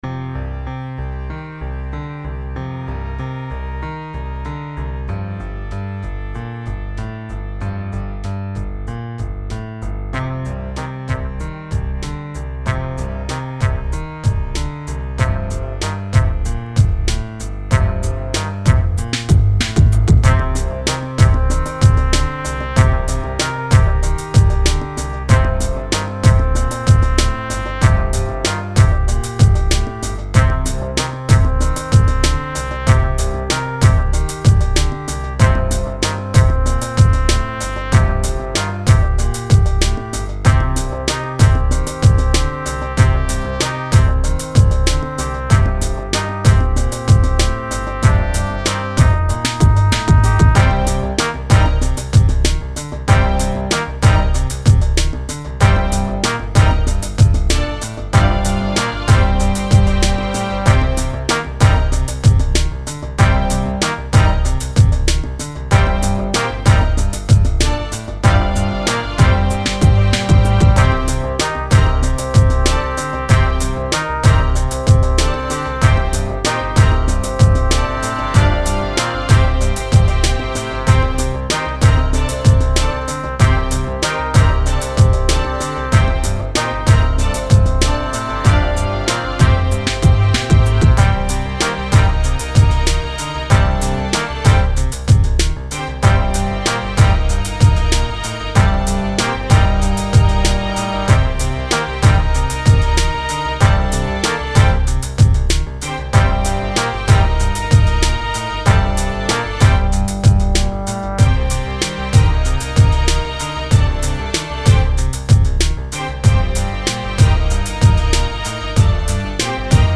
Default new track with classical instruments
Nice kick...very deep! The instuments seem a bit dry, is there much FX/processing done?
I like the programming of the horns/brass....
Actually I used quiet a lot of reverb but maybe should use another one. There is a long RT60 but I think it sounds a bit artificial.
I made the whole track in reason excepted the piano sound is a Virus preset. and I used an "Izotope Trash" effect for the beat.